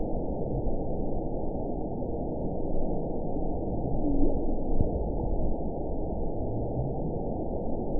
event 919749 date 01/20/24 time 17:15:36 GMT (1 year, 4 months ago) score 8.24 location TSS-AB09 detected by nrw target species NRW annotations +NRW Spectrogram: Frequency (kHz) vs. Time (s) audio not available .wav